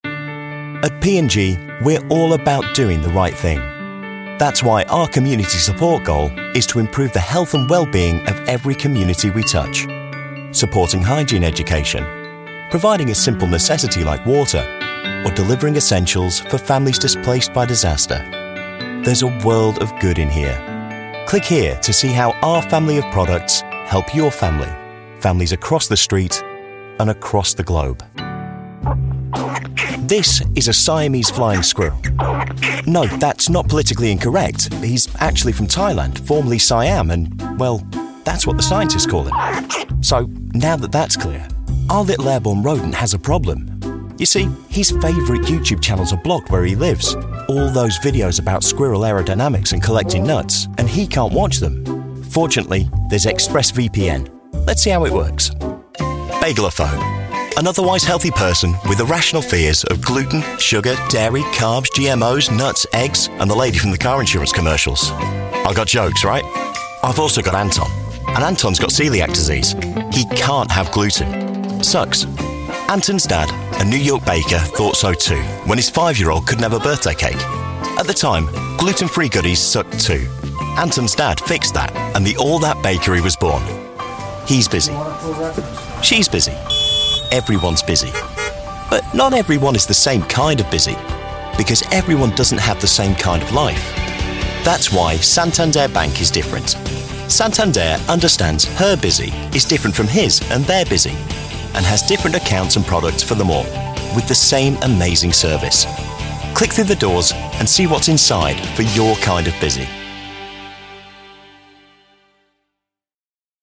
Male
Warm, Assured, Authoritative, Bright, Bubbly, Character, Cheeky, Confident, Cool, Corporate, Deep, Gravitas, Sarcastic, Smooth, Soft, Wacky, Witty, Engaging, Friendly, Natural, Reassuring, Versatile
British English, RP, Northern, Estuary, General Scottish, Genereric Irish
Explainer_Conversational.mp3
Microphone: Neumann U87ai, Sennheiser 416,
Audio equipment: Audient ID22, Studiobricks Sound Booth